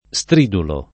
stridulo [ S tr & dulo ]